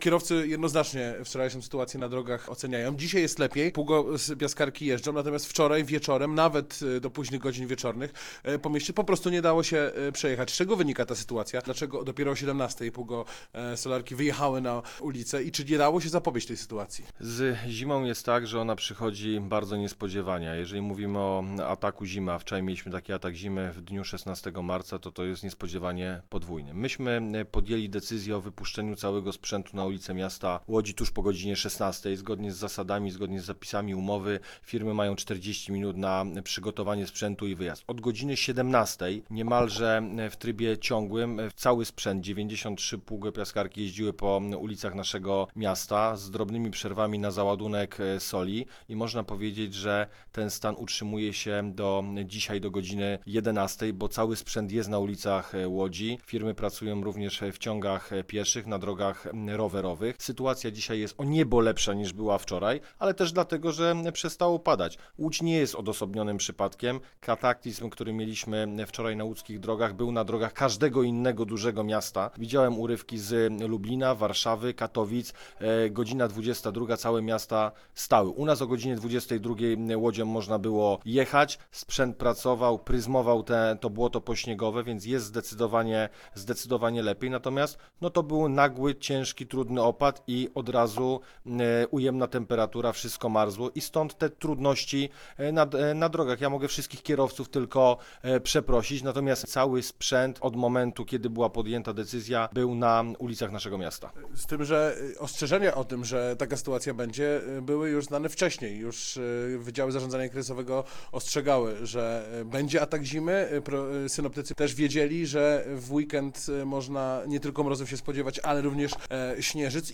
Wiceprezydent Łodzi - odpowiedzialny za odśnieżanie miasta - Tomasz Trela przeprasza kierowców za piątkowe warunki na drogach. W rozmowie z Radiem Łódź podkreśla jednak, że przy tak intensywnych opadach nie dało się uniknąć ani korków, ani nieodśnieżonych jezdni.